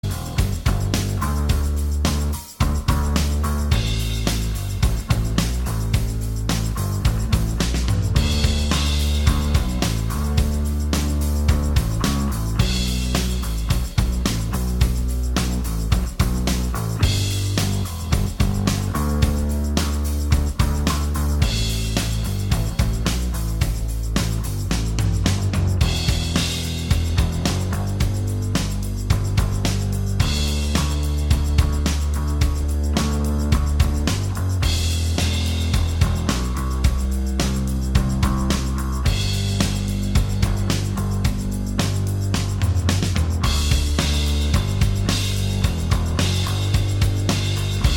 Minus Main Guitar Indie / Alternative 5:40 Buy £1.50